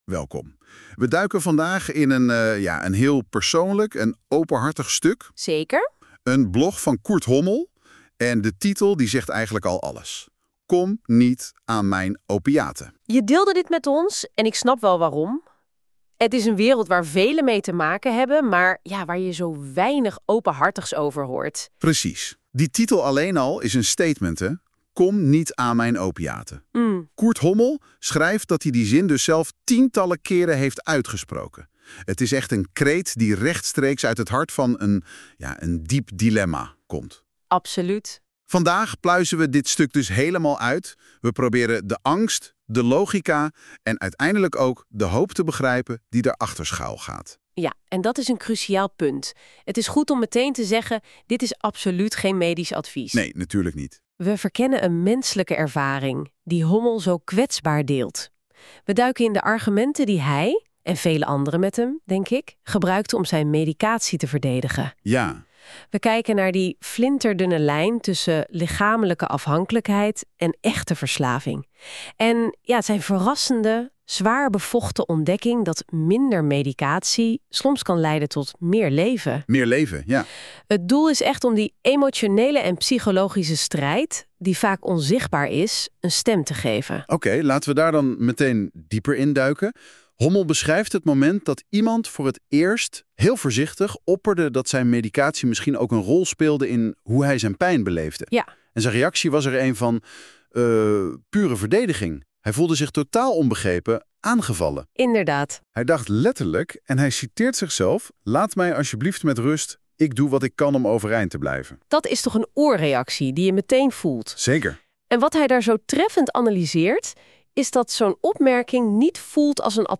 Met hulp van AI genereerde ik een podcast-gesprek over dit blog.